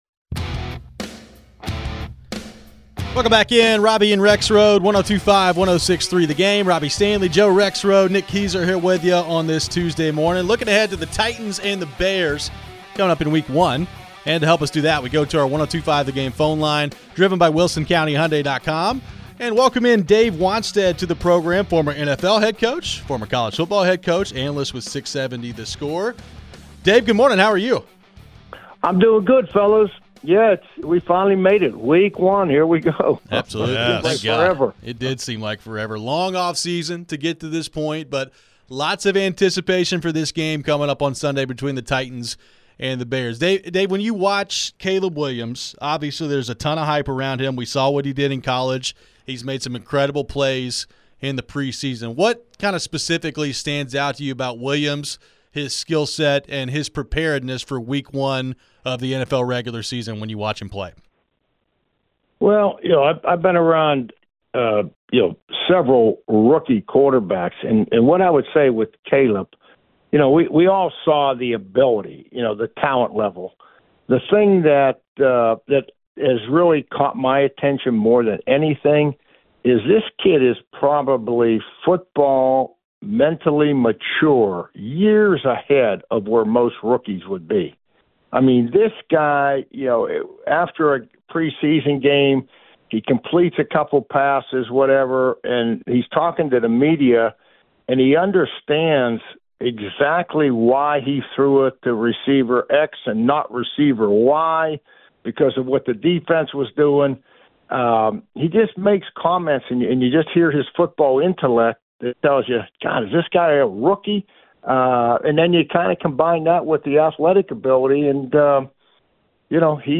Dave Wannstedt Interview (9-3-24)